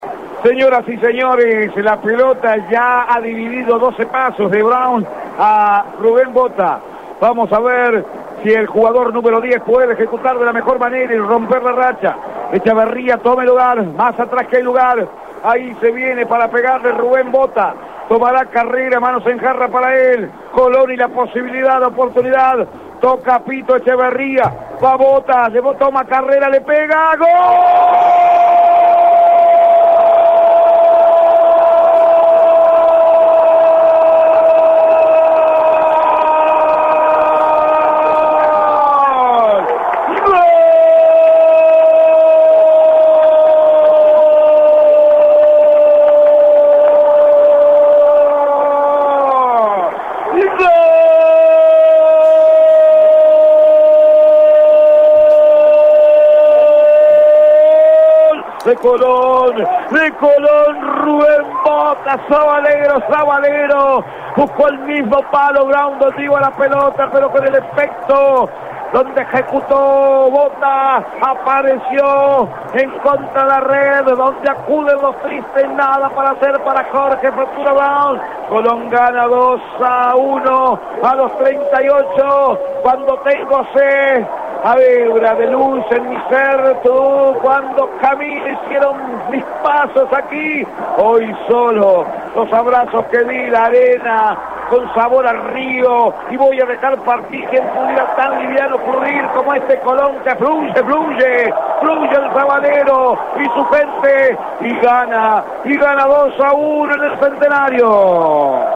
02-GOL-COLON-1.mp3